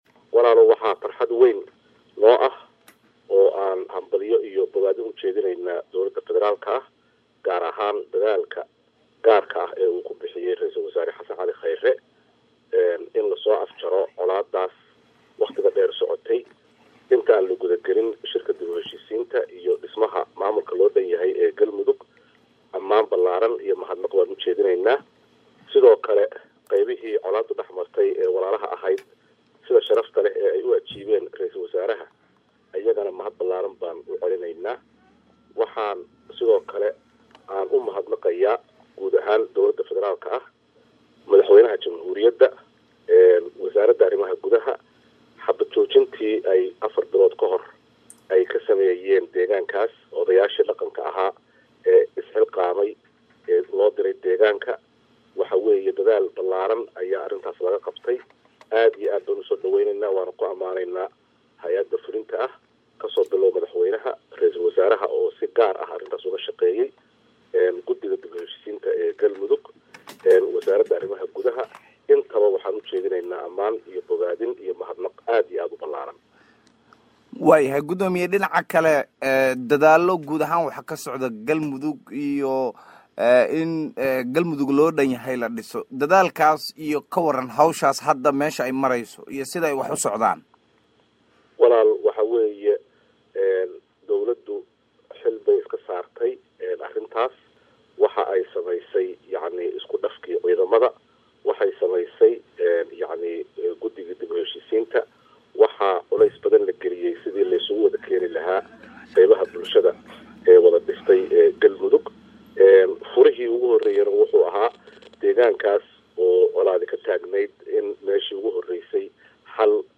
Guddoomiye ku xigeenka 2aad ee Golaha Shacabka Mudane Mahad Cabdalla Cawad oo wareysi gaar ah siiyey Radio Muqdisho codka Jamhuuriyadda Federaalka Soomaaliya ayaa tilmaamay in uu bogaadinayo dadaallada dib u heshiisiinta gaar ahaanna dadaalladii ugu dambeeyey ee lagu heshiisiiyey beelaha walaalaha ee Xeraale iyo Huurshe.
WAREYSI-GUDOOMIYE-KU-XIGEENKA-LABAAD-EE-GOLAHA-SHACABKA-MAHD-CAWAD-1.mp3